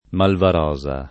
malvarosa [ malvar 0@ a ] s. f. (bot.)